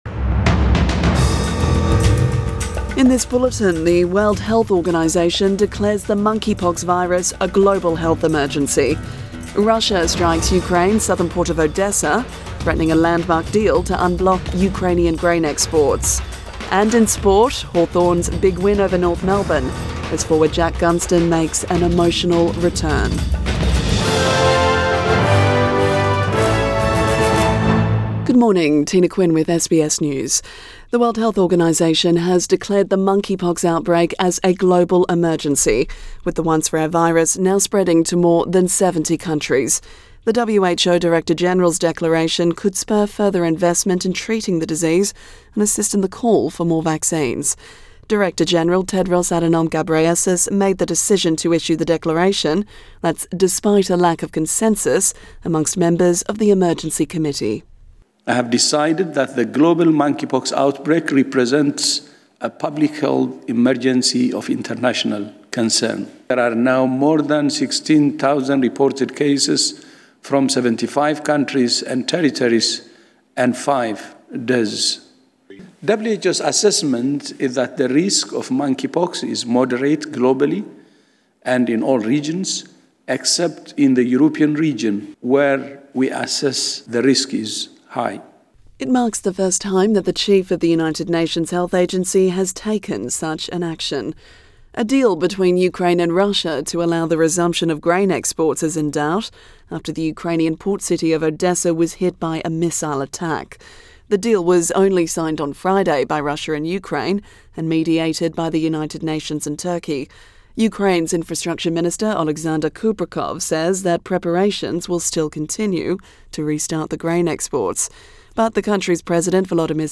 AM bulletin 24 July 2022